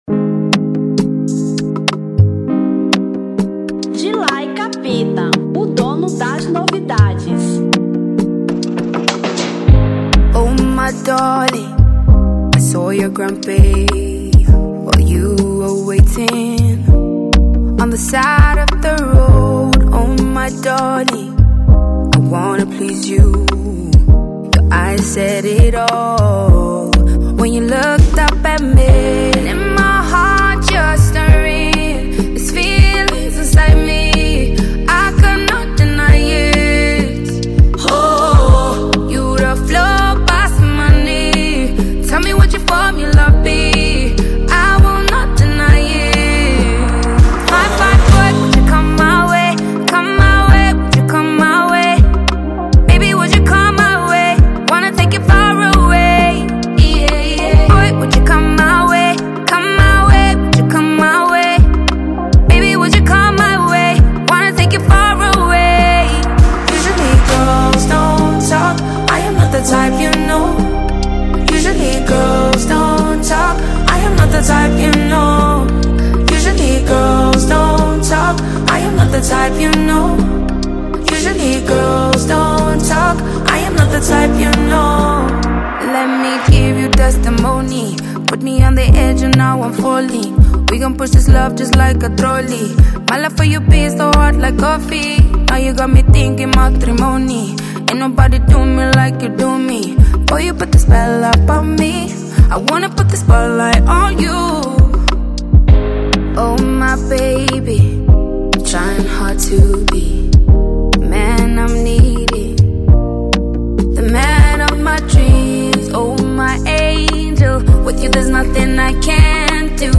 Amapiano 2025